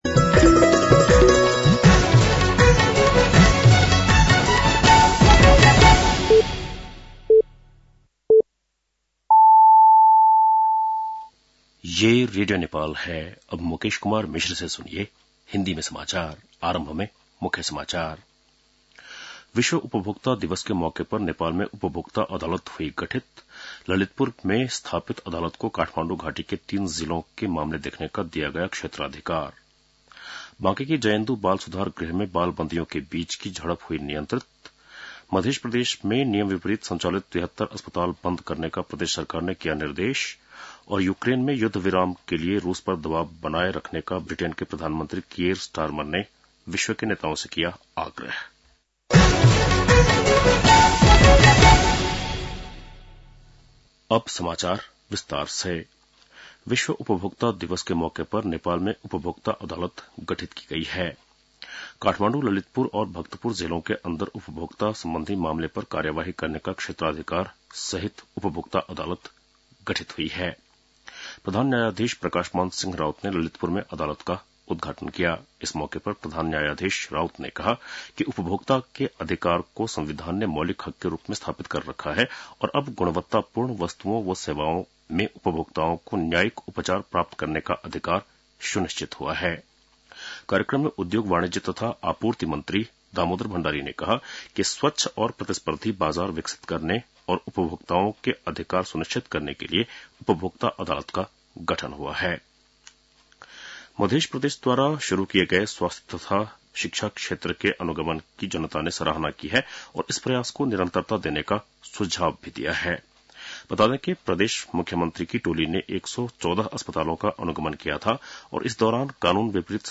बेलुकी १० बजेको हिन्दी समाचार : २ चैत , २०८१